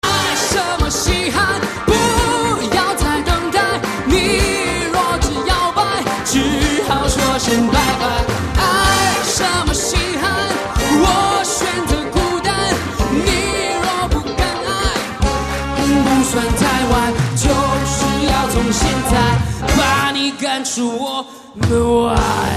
M4R铃声, MP3铃声, 华语歌曲 67 首发日期：2018-05-15 22:36 星期二